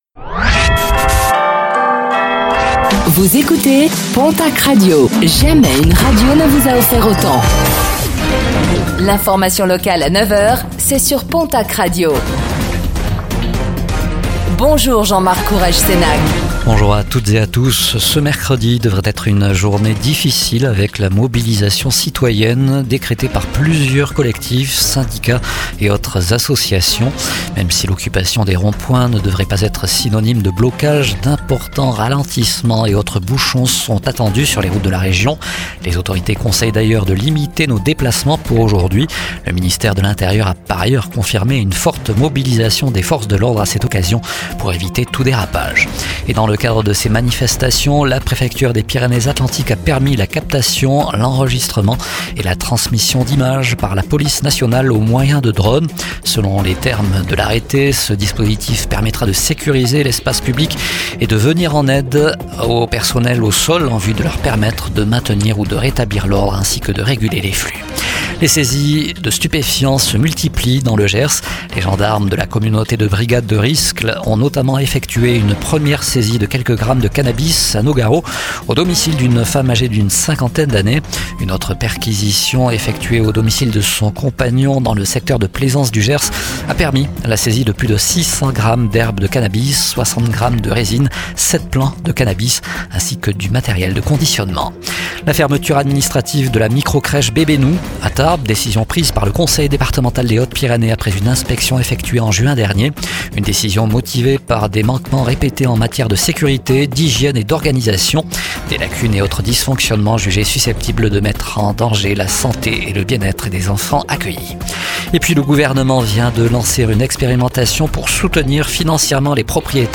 Infos | Mercredi 10 septembre 2025 - PONTACQ RADIO